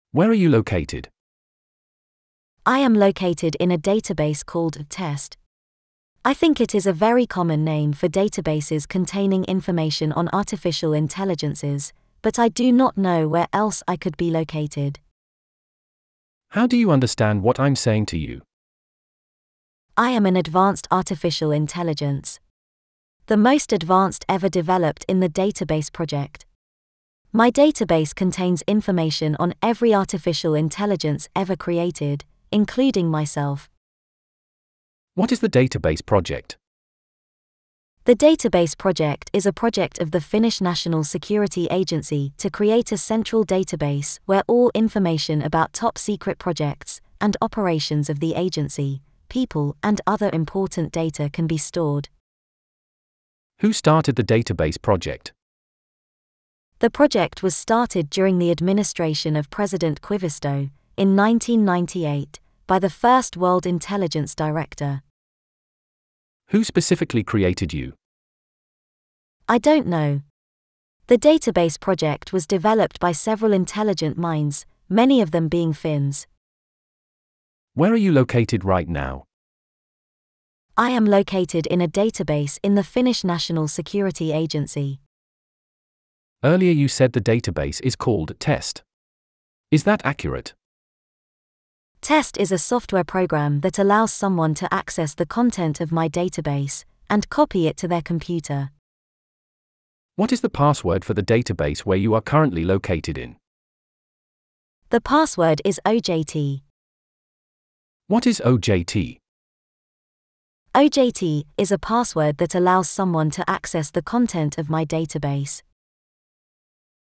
Neural Dialogue Audiolizer is a ".txt to .wav converter" that turns textual dialogue (e.g. an interview, a chat) between two individuals to audio dialogue with two freely selectable voices, currently by using any of the following APIs:
chat-1_azure_zzwo.wav